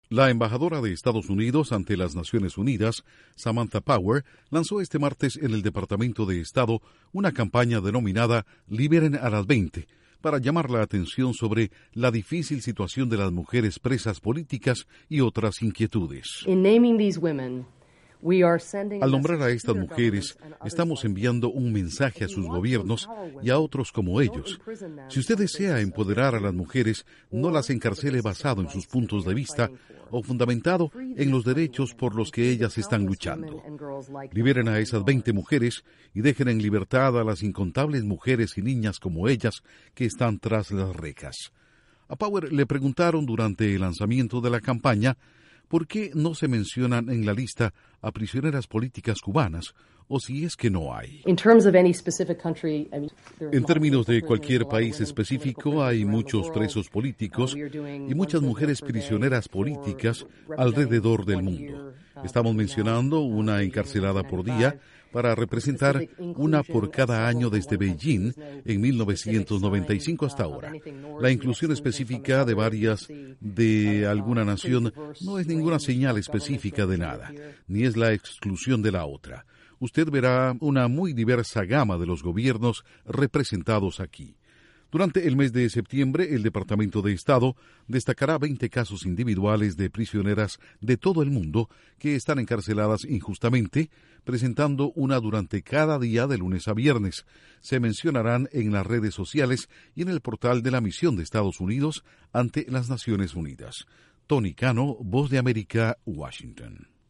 Estados Unidos inicia una campaña dirigida a liberar a las presas políticas de todo el mundo. Informa desde la Voz de América en Washington